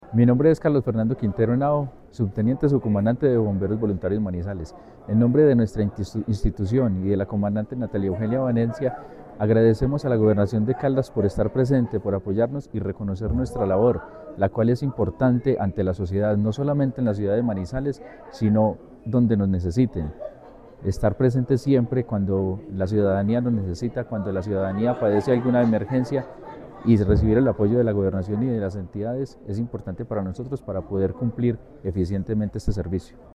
La Secretaría de Medio Ambiente de Caldas y su Jefatura de Gestión del Riesgo, participó en la ceremonia conmemorativa por los 100 años del Benemérito Cuerpo de Bomberos Voluntarios de Manizales, una de las instituciones emblemáticas del departamento por su compromiso con la atención de emergencias y la protección de la vida.